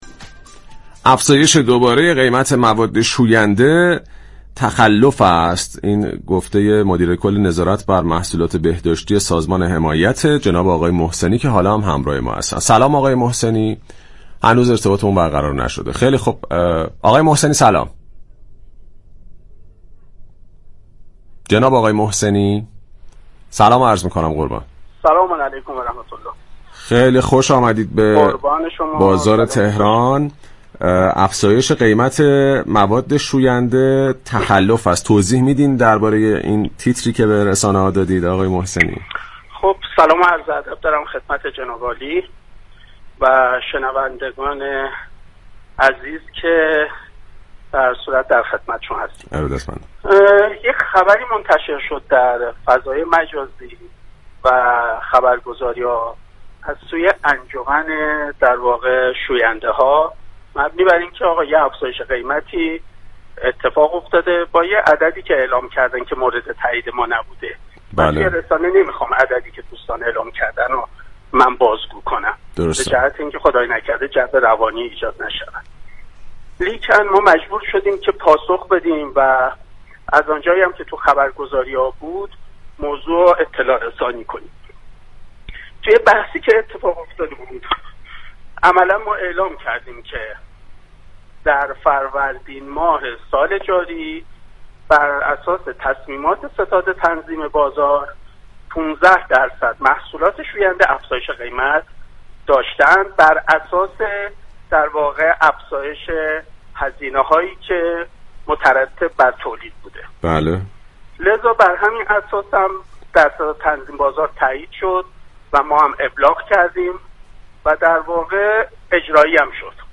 به گزارش پایگاه اطلاع رسانی رادیو تهران، رضا محسنی مدیر‌كل نظارت بر محصولات بهداشتی، غذایی و دارویی سازمان حمایت از مصرف‌كنندگان و تولیدكنندگان در گفت و گو با «بازار تهران» اظهار داشت: هر‌گونه افزایش قیمت مواد شوینده بیش از 15 درصد كه در فروردین‌ سال جاری به تصویب ستاد تنظیم بازار رسیده است و تخلف و مورد تایید سازمان حمایت از مصرف‌كنندگان و تولیدكنندگان نیست.